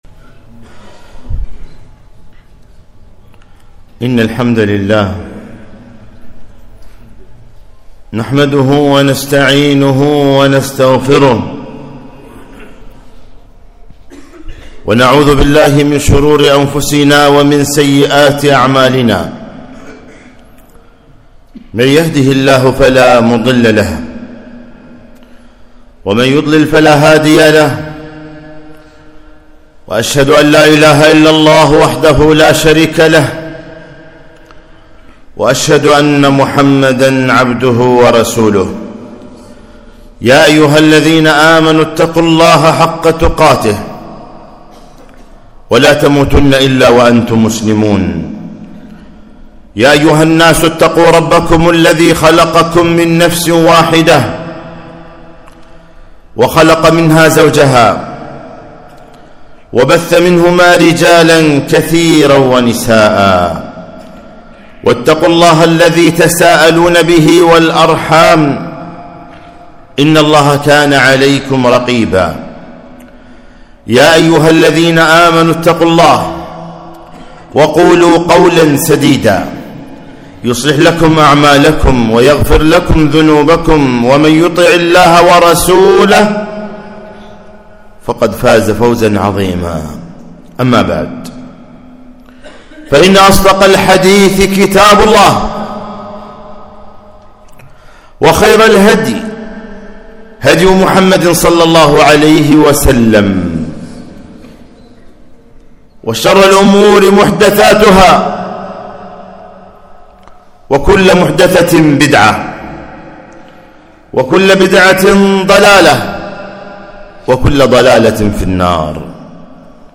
خطبة - نعمة الهداية